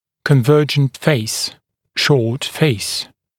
[kən’vɜːʤənt feɪs] [ʃɔːt feɪs][кэн’вё:джэнт фэйс] [шо:т фэйс]укороченное лицо, конвергентное лицо